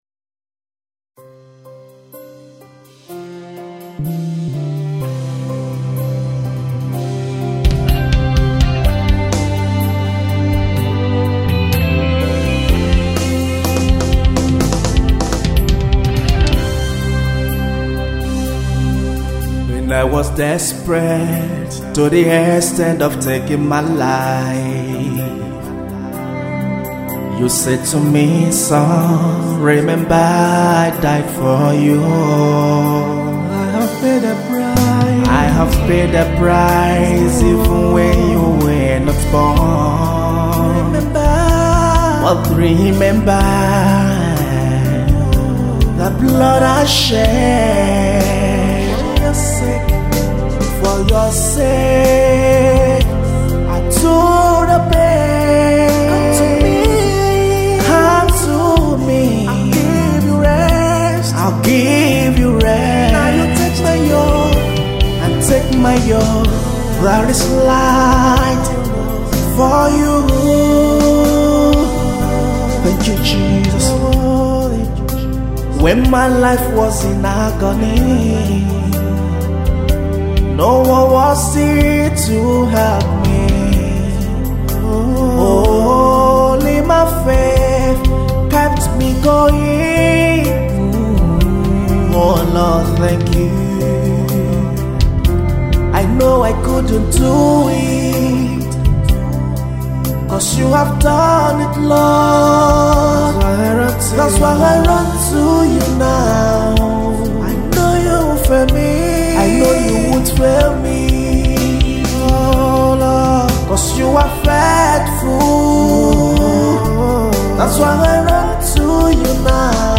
UAE/Nigeria born gospel music minister and recording artiste